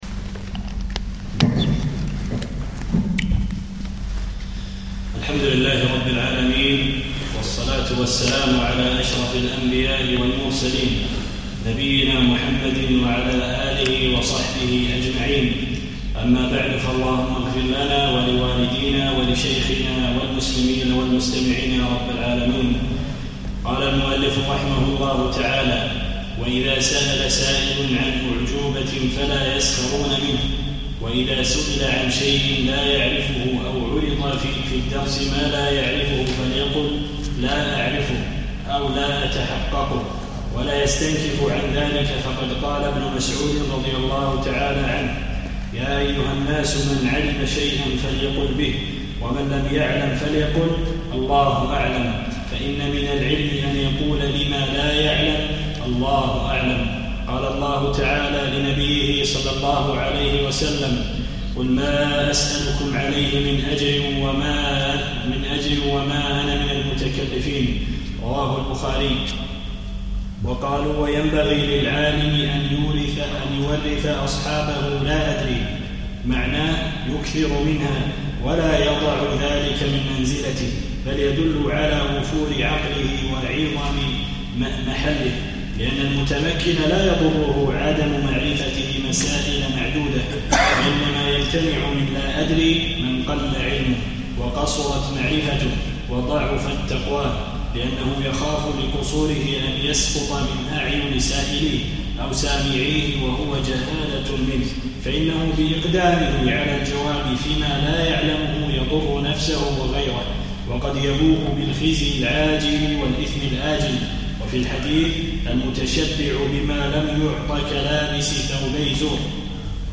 الخميس 23 جمادى الأولى 1437 الموافق 3 3 2016 مسجد الرويح الزهراء
الدرس الرابع